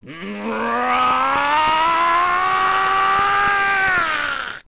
OOT_Ganondorf_Yell.wav